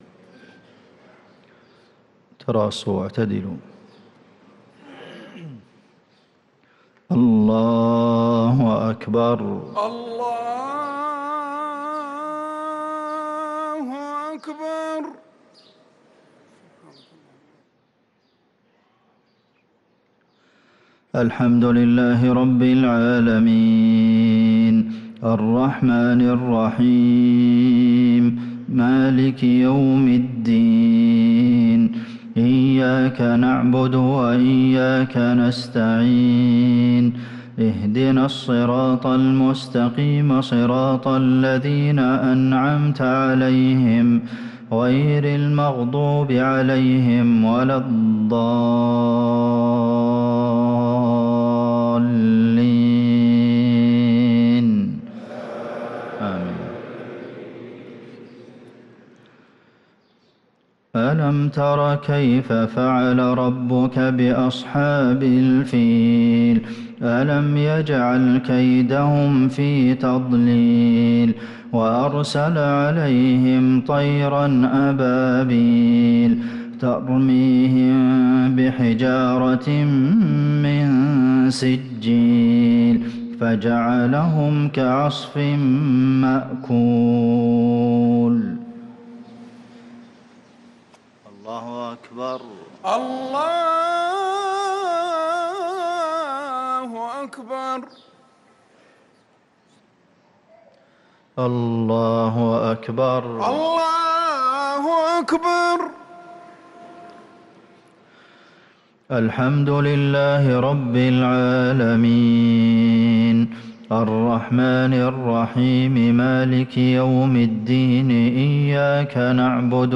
صلاة المغرب للقارئ عبدالمحسن القاسم 28 ربيع الآخر 1445 هـ